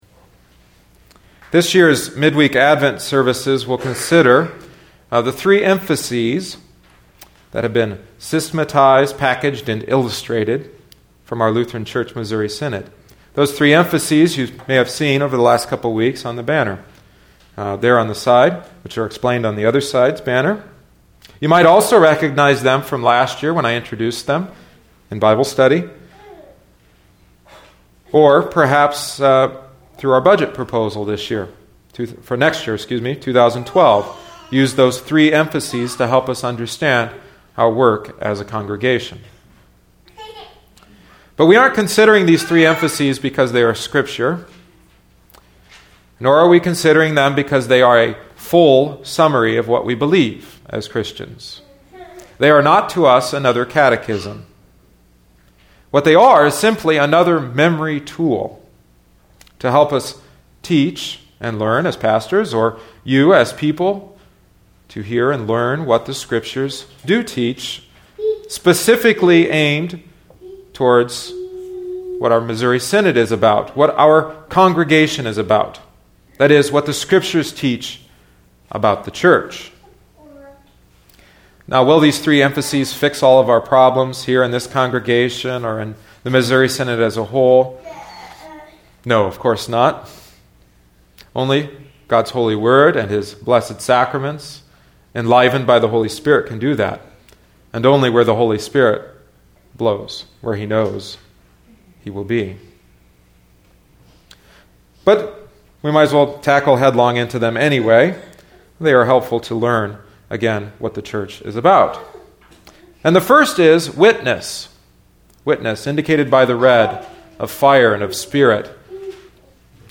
A preaching series based on and drawn from John Pless’s outline and Al Collver’s Bible study materials.